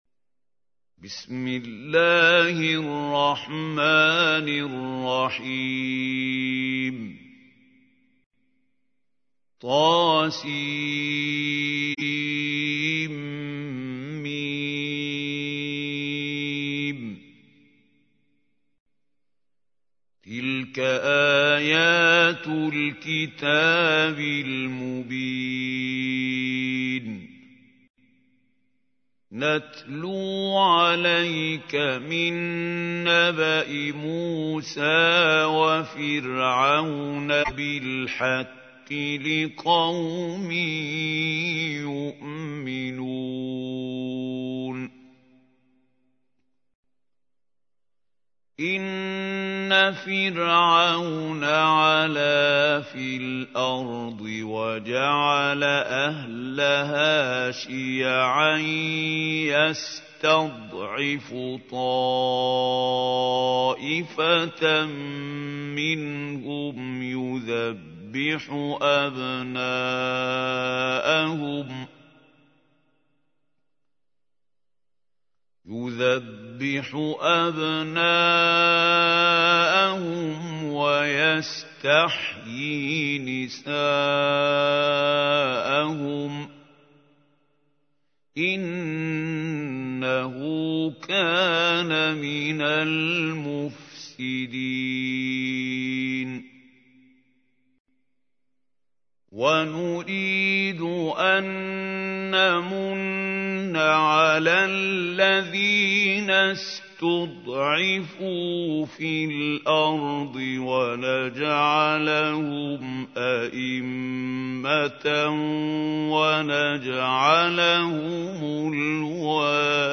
تحميل : 28. سورة القصص / القارئ محمود خليل الحصري / القرآن الكريم / موقع يا حسين